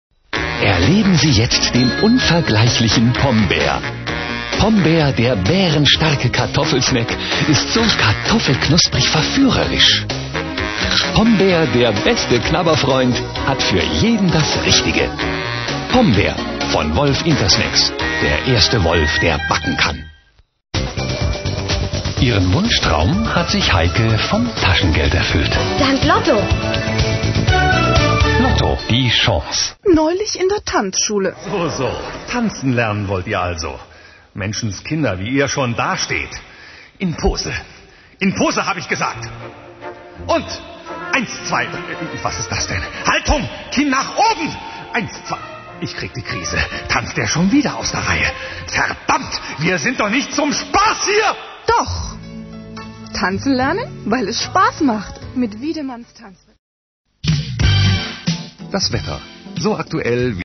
Kein Dialekt
Sprechprobe: Industrie (Muttersprache):
german voice over artist